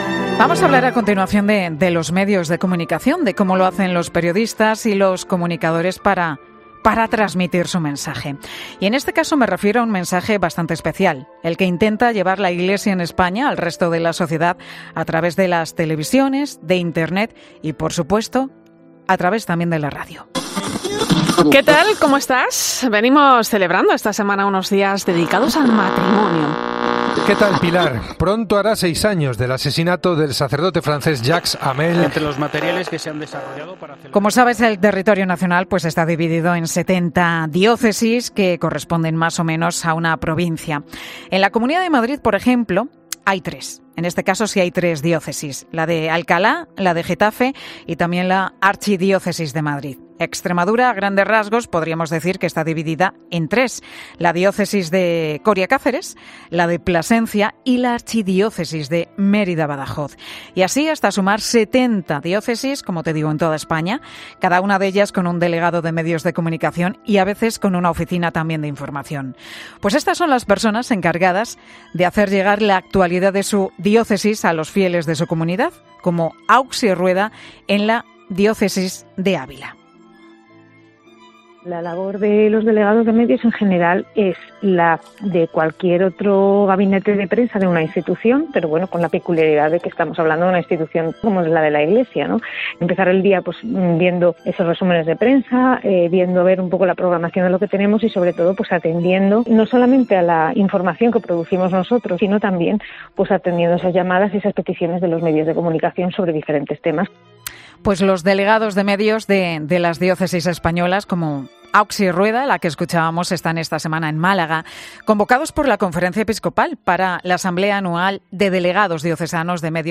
En 'Mediodía COPE' hemos hablado de medios de comunicación, de cómo lo hacen los periodistas y comunicadores para hacer llegar su mensaje. En este caso, un mensaje muy especial: el que intenta llevar la Iglesia en España al resto de la sociedad.